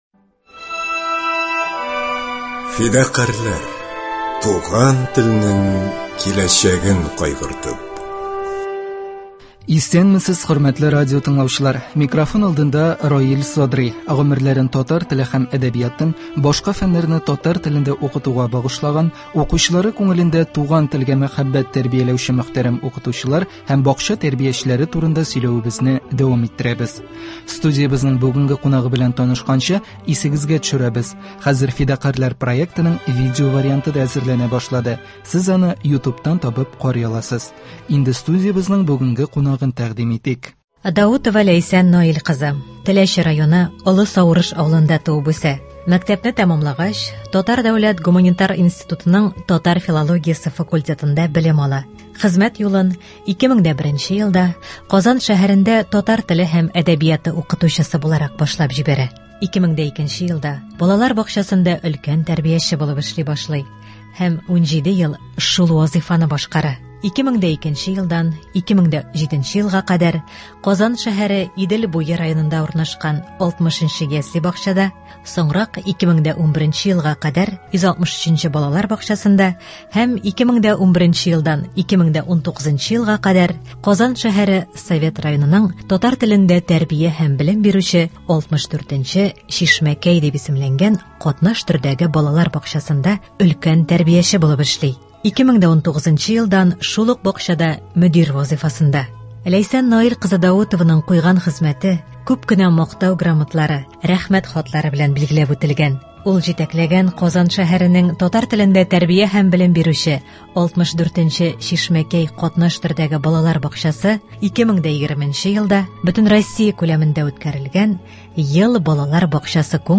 Студиябезнең бүгенге кунагы